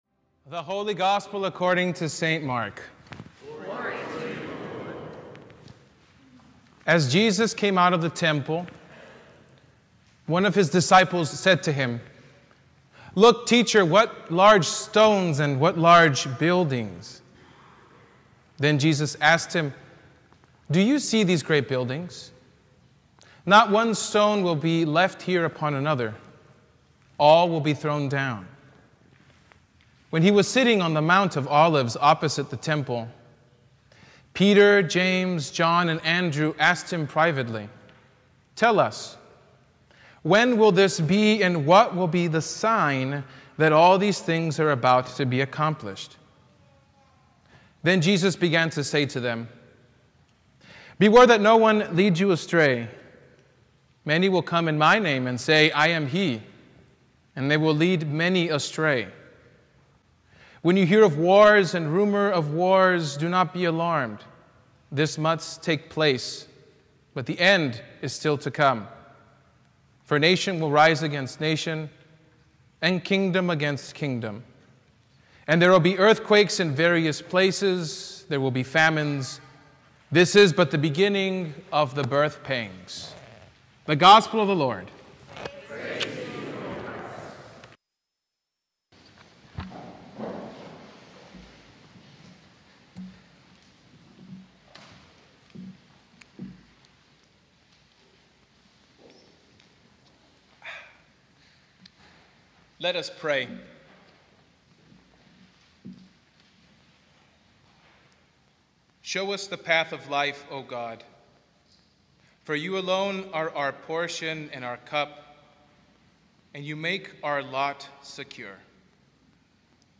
Sermon from the Twenty-sixth Sunday After Pentecost